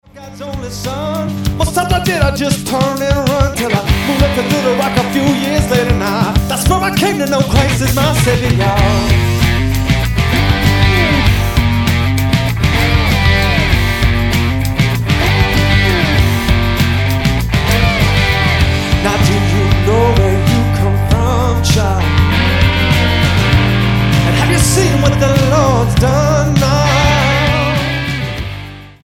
gritty gospel blues singer
Style: Rock